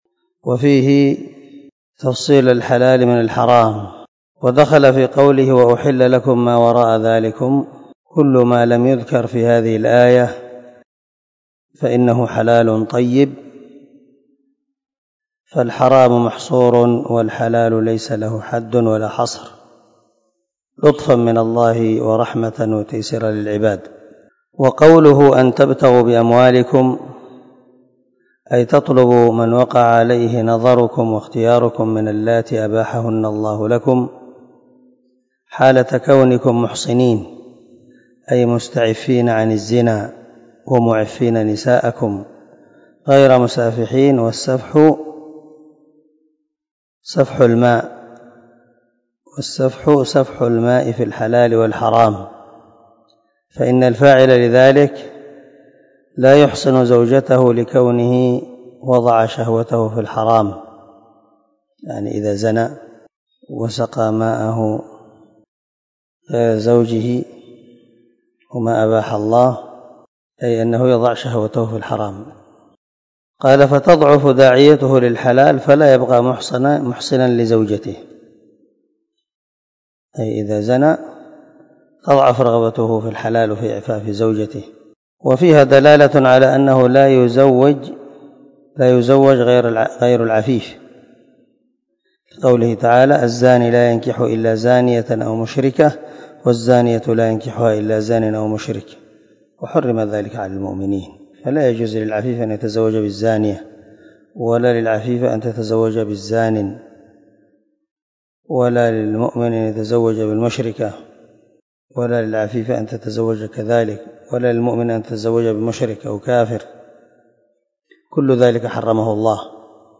253الدرس 21 تفسير آية ( 25 ) من سورة النساء من تفسير القران الكريم مع قراءة لتفسير السعدي